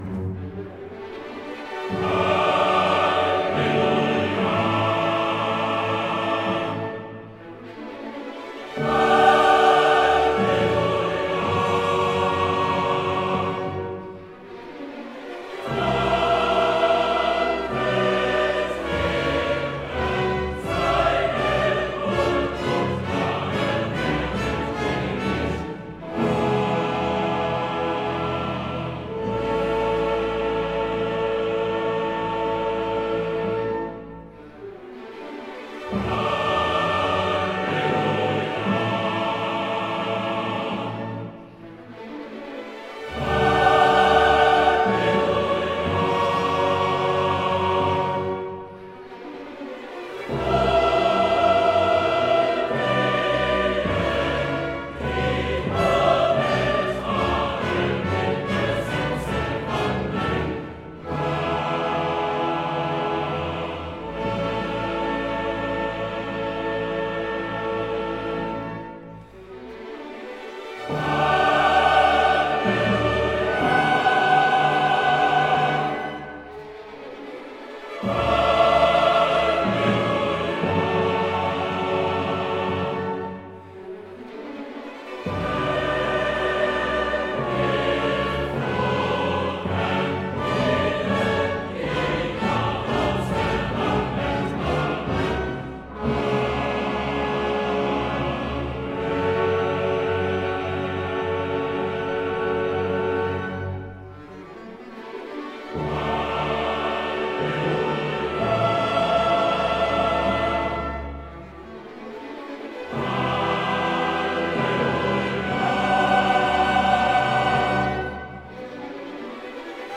这里，他使用了大型管弦乐队，一位主要男高音，其它四名独唱演员，一个尽可能大的合唱队和管风琴。
也许清唱剧中最大胆的部分是光彩耀眼的胜利合唱“哈利路亚”。这里大胆地采用匈牙利吉普赛人的韵律，不断加速的音乐，表现了激动人心的庆典。